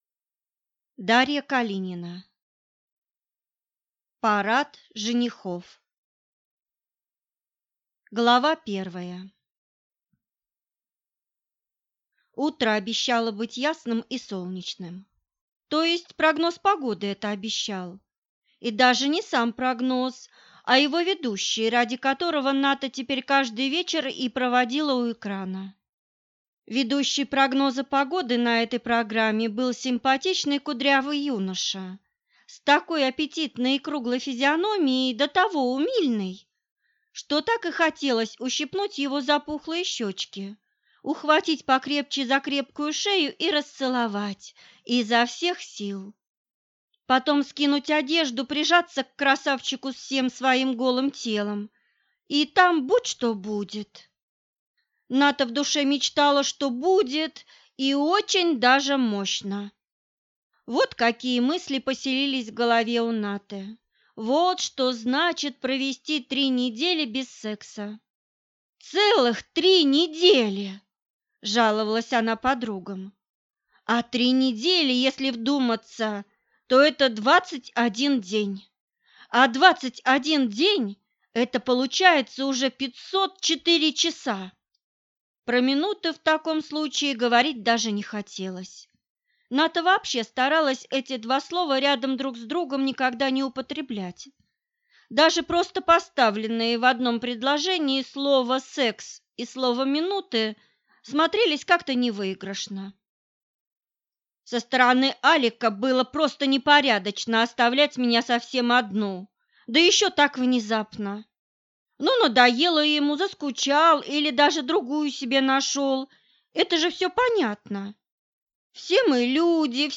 Аудиокнига Парад женихов | Библиотека аудиокниг